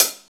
paiste hi hat3 close.wav